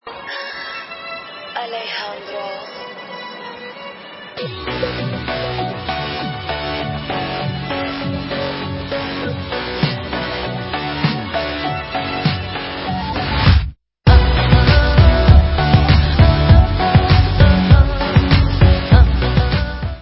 sledovat novinky v kategorii Dance